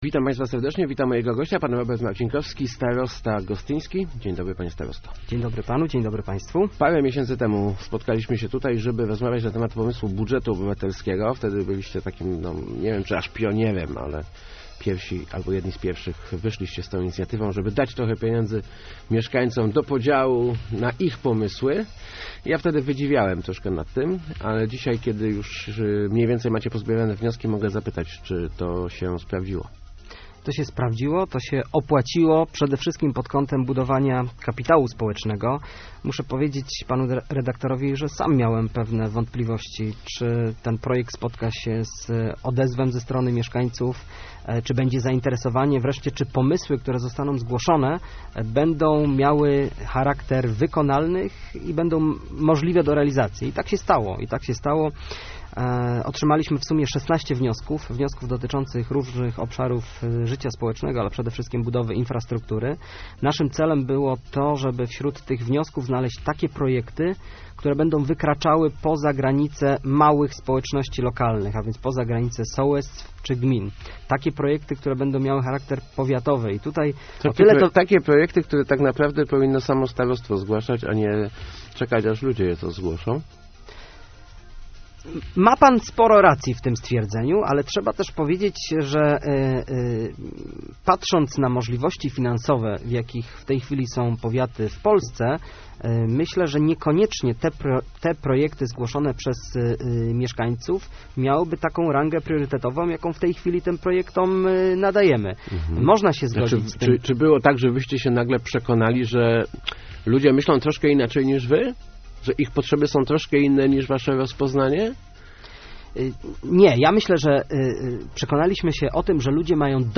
To był bardzo udany eksperyment - mówił w Rozmowach Elki starosta gostyński Robert Marcinkowski, oceniając pierwszy nabór wniosków do budżetu obywatelskiego. Spośród kilkunastu propozycji do realizacji trafi pięć, w tym ścieżka rowerowa Gostyń-Grabonóg.